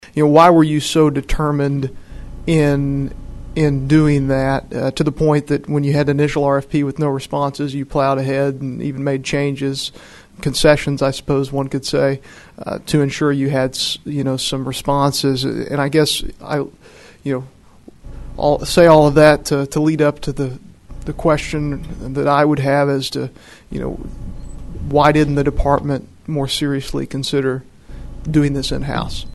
Missouri House panel questions medical marijuana program’s choices, calls on RFP agency to testify (AUDIO FROM HEARING)
Rep. Dirk Deaton of southwest Missouri, R-Noel, questioned the decision: (:29)